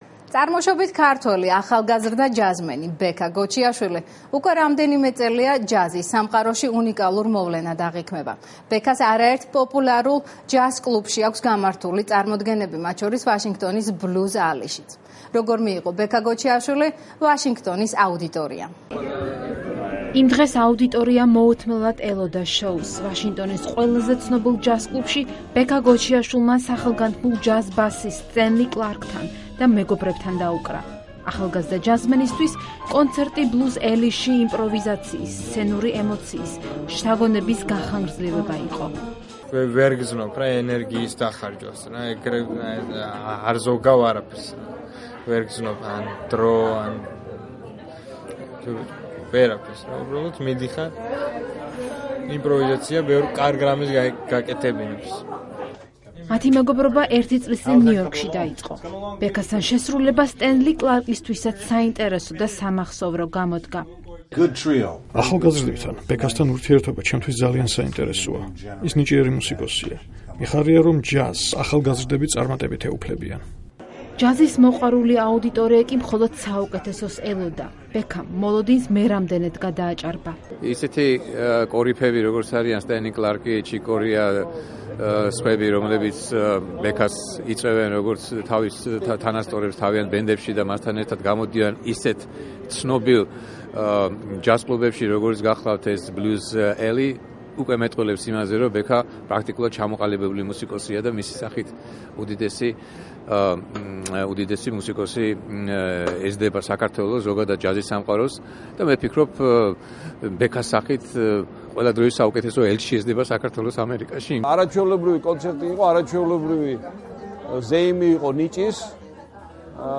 ახალგაზრდა ქართველი ჯაზ-პიანისტის პრესტიჟულ ჯაზ კლუბში გამართულმა კონცერტმა ამერიკელი მსმენელი კიდევ ერთხელ მოხიბლა